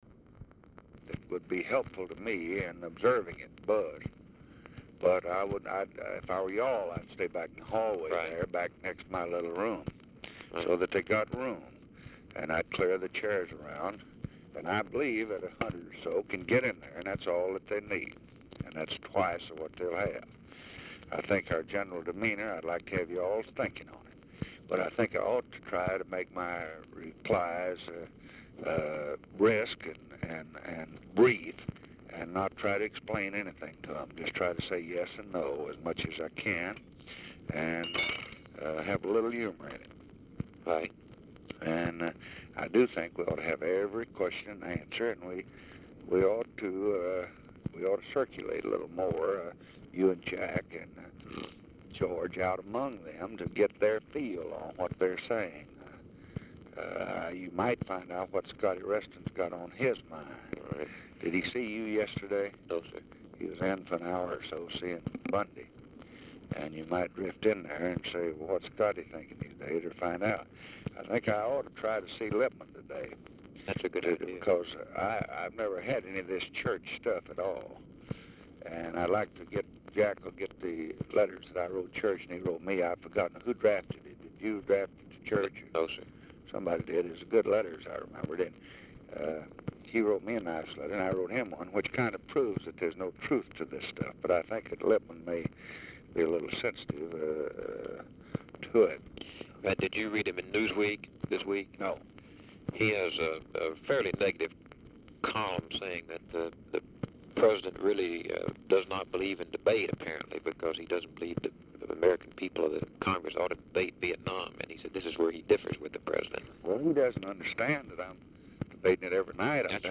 Telephone conversation # 7051, sound recording, LBJ and BILL MOYERS, 3/10/1965, 9:40AM
RECORDING STARTS AFTER CONVERSATION HAS BEGUN
Format Dictation belt
Location Of Speaker 1 Mansion, White House, Washington, DC